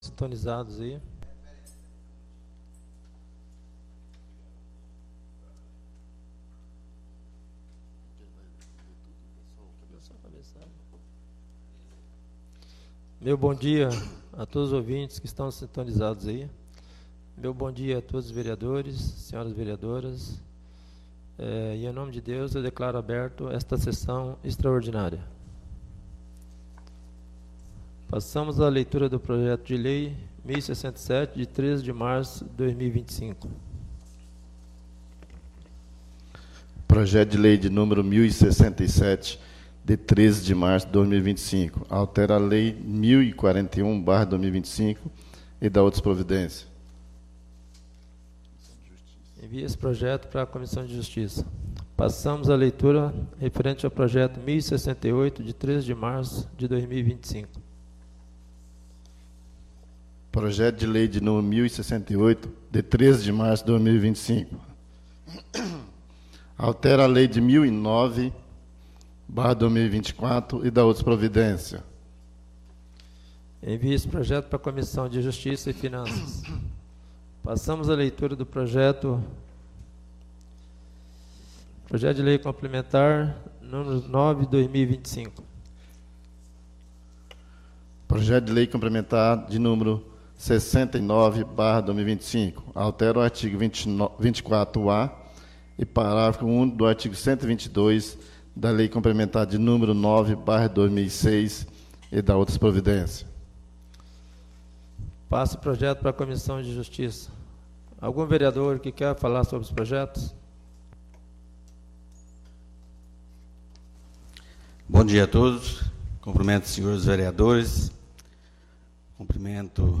3° SESSÃO EXTRAORDINÁRIA DE 17 DE MARÇO DE 2025